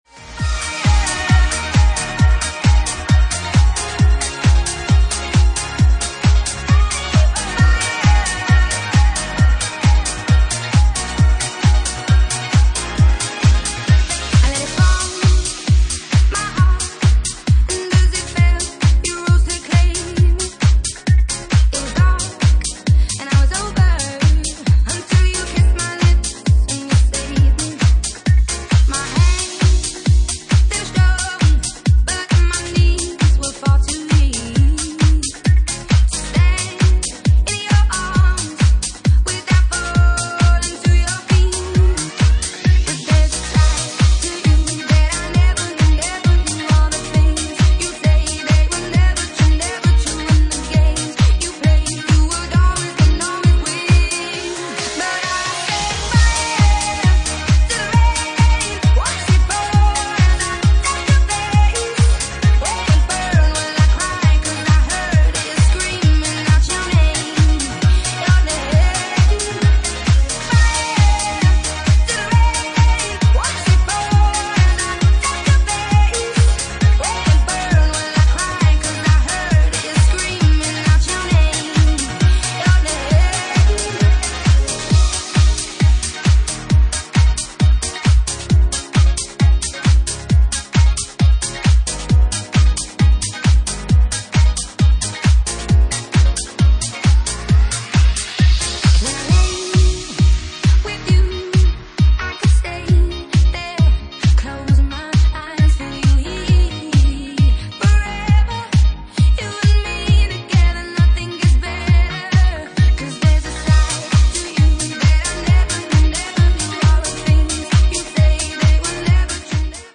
Genre:Bassline House
Bassline House at 134 bpm